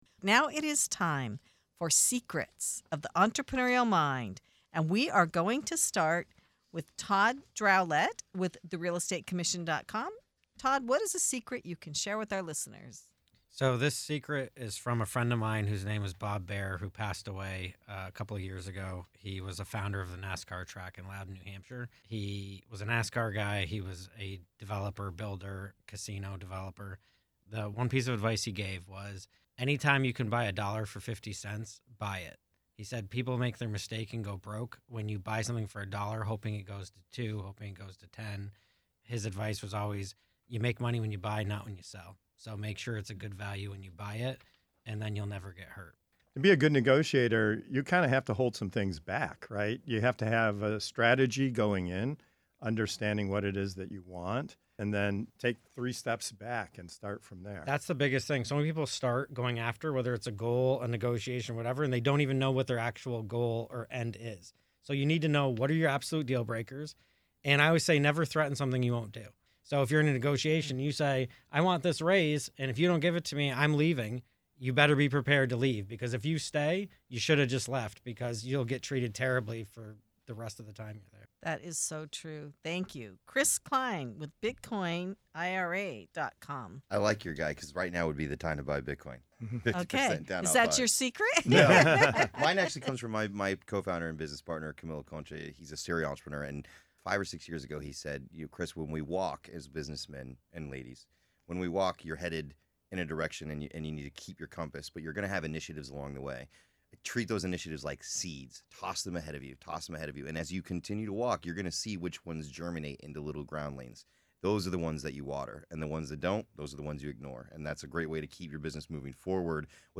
In this segment of "Secrets of the Entrepreneurial Mind" on Passage to Profit Show, our panel reveals powerful entrepreneurial tactics about mindset, negotiation, and spotting opportunity before others do. The discussion explores why successful entrepreneurs believe you make money when you buy, not when you sell, how to approach negotiations with clear goals and real leverage, and why testing ideas like “seeds” can help founders discover which opportunities are worth pursuing.